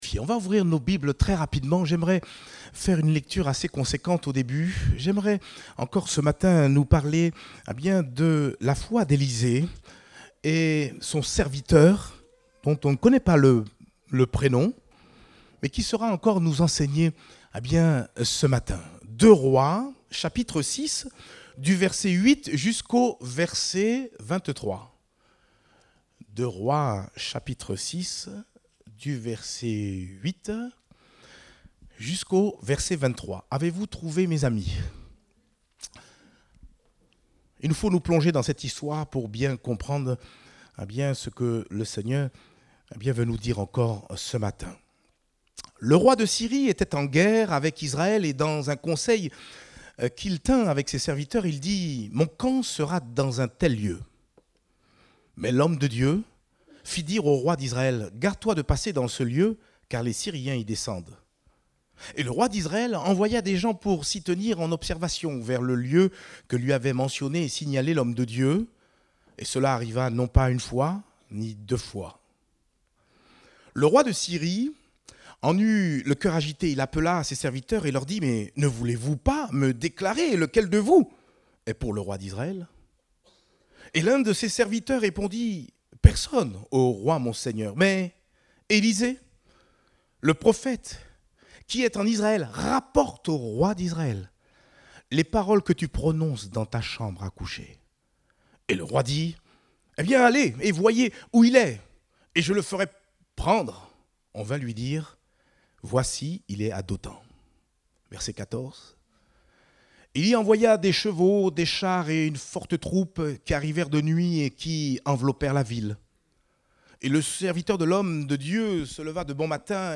Date : 2 avril 2023 (Culte Dominical)